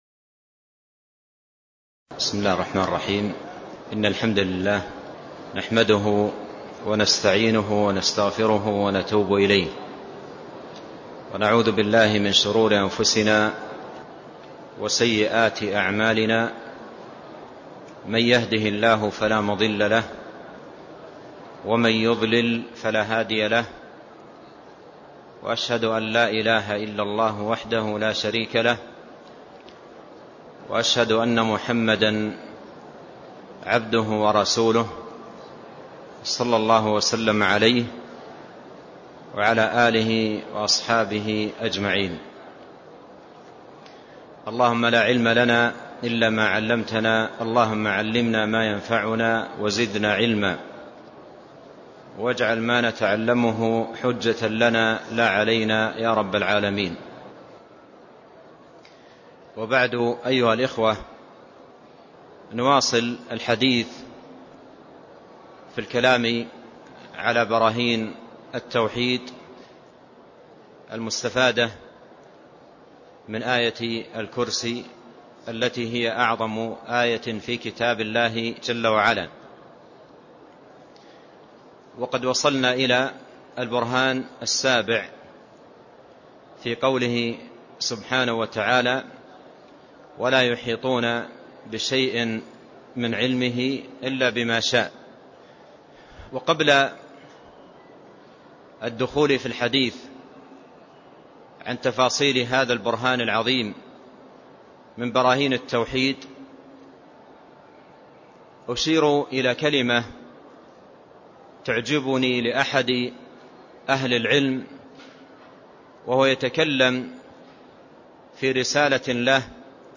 تاريخ النشر ١٩ جمادى الآخرة ١٤٢٧ هـ المكان: المسجد النبوي الشيخ: فضيلة الشيخ عبد الرزاق بن عبد المحسن البدر فضيلة الشيخ عبد الرزاق بن عبد المحسن البدر براهين التوحيد من آية الكرسي (015)